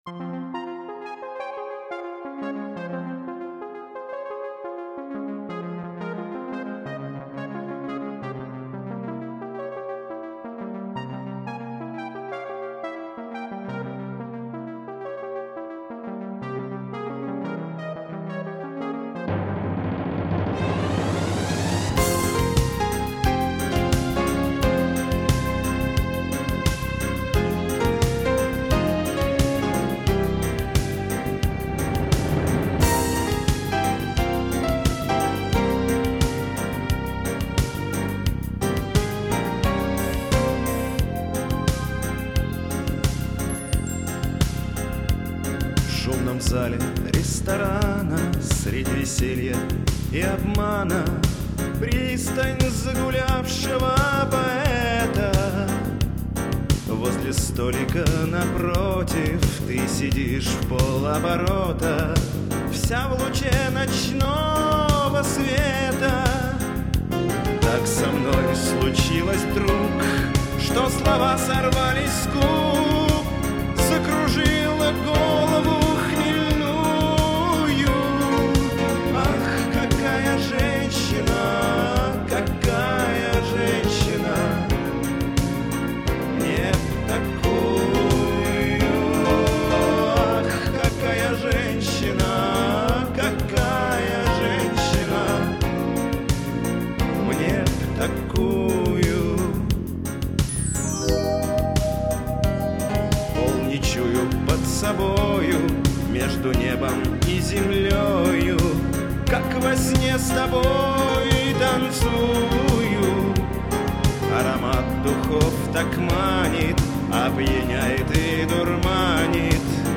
Ну и пусть ругаются, что попсятина кондовая и китч.